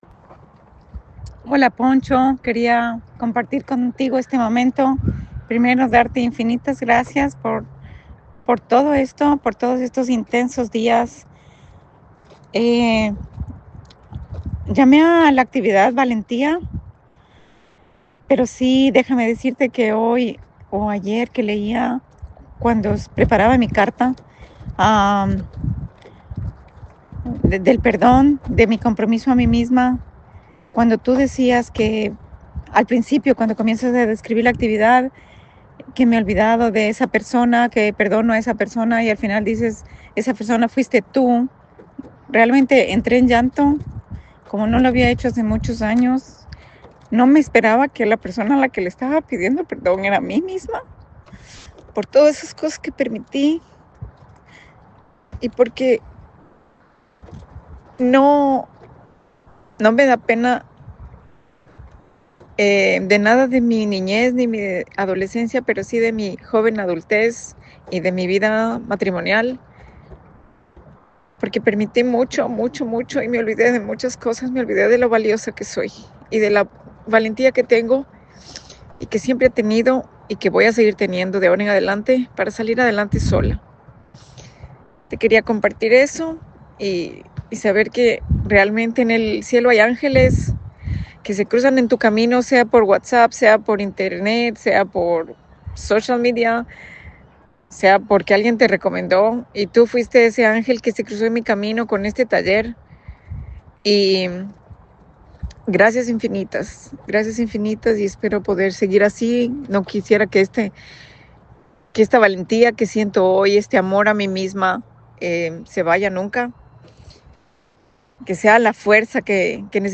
Testimoniales